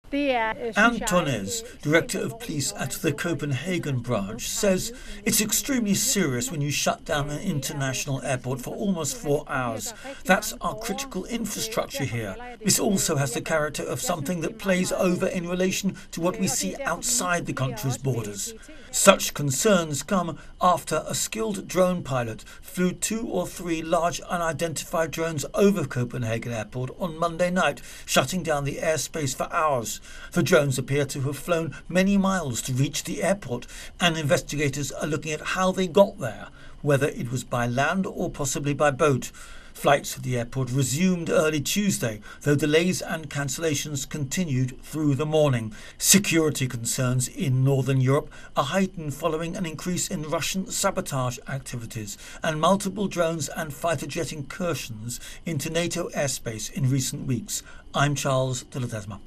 AP correspondent
reports